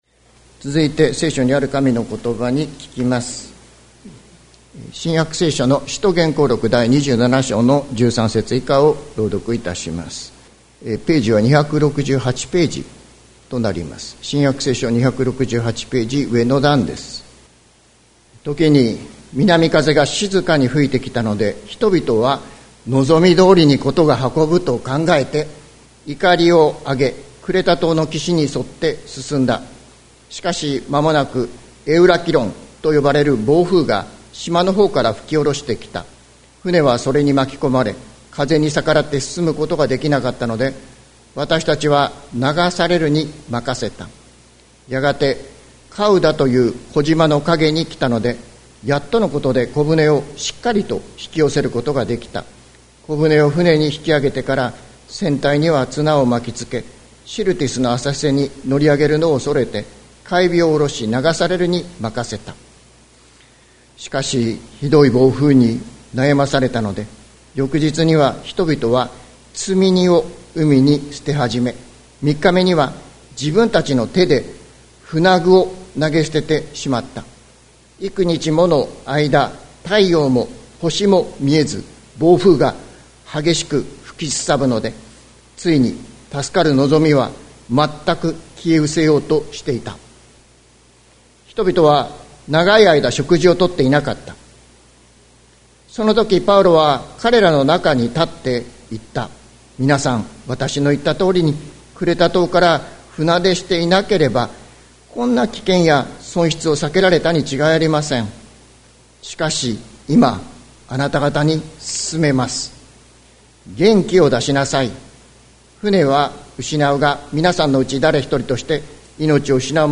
2026年01月01日朝の礼拝「元気を出しなさい」関キリスト教会
説教アーカイブ。